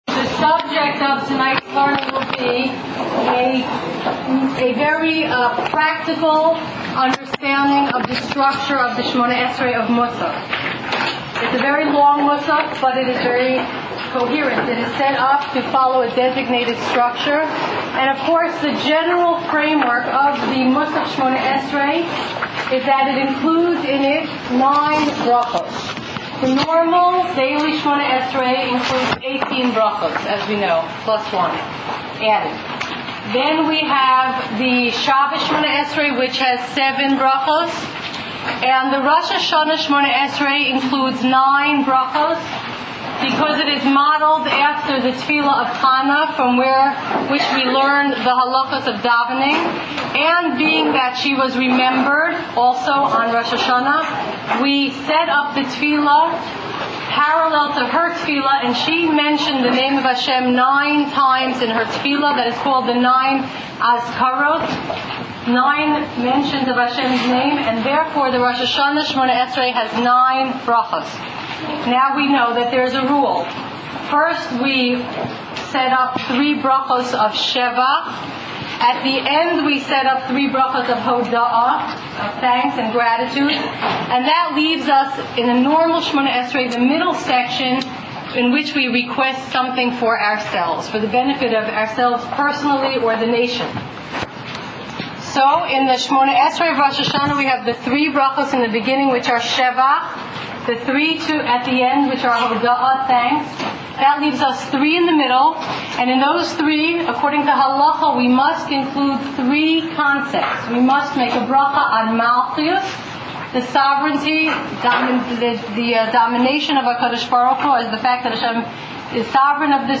Shiur on Tefillat Musaf Rosh Hashana